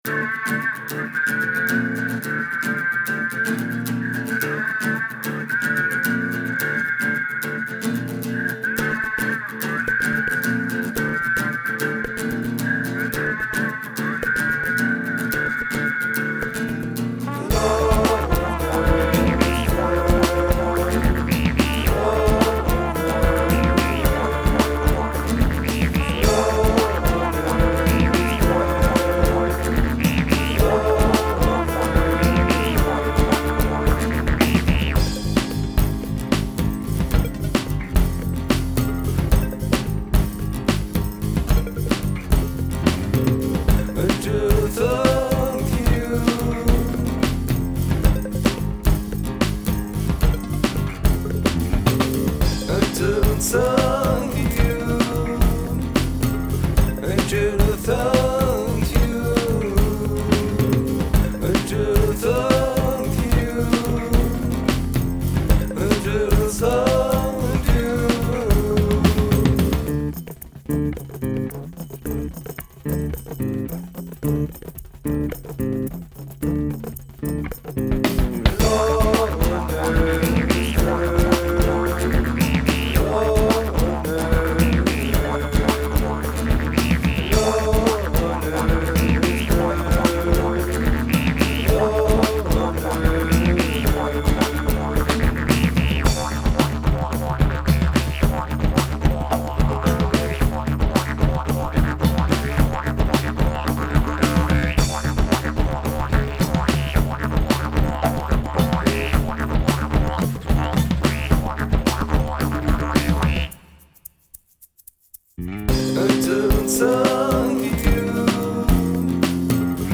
Y a du didge, évidemment, mais pas tout le temps, et comme d'hab il ne sert que l'accompagnement, donc il est pô très fort!! :langue:
Excellent ce morceau et pas si bordélique que ça, je trouve que ça créé plein de mouvements bien intéressant.
Mais très sympa, et pour le coup dans tout ce que ça m'évoque, là c'est le bordel (jazz, électro, funk, chanson française etc...)
tout est bien pour moi, le didg à bonne entendeur, juste comme il faut, bien placé, les autres instruments qui accompagnent, juste bien, les voix impec., bonne rythmique...........